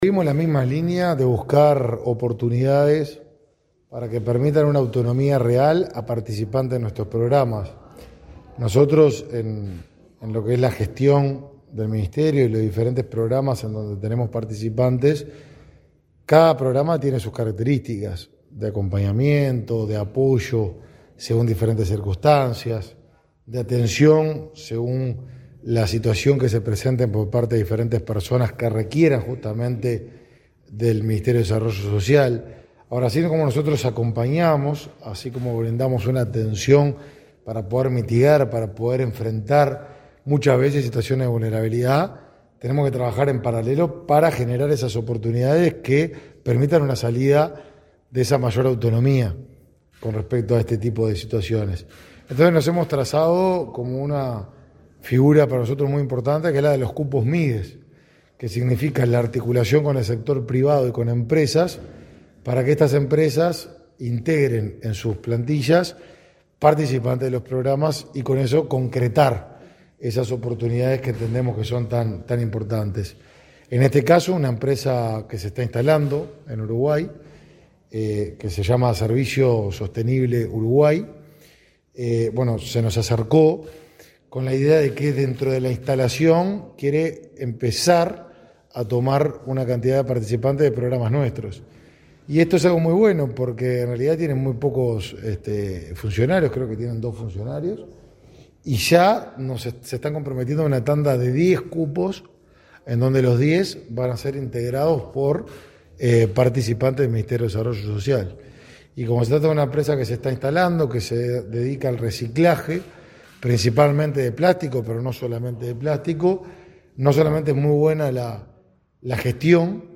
Entrevista al ministro de Desarrollo Social, Martín Lema